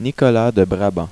(french pronunciation)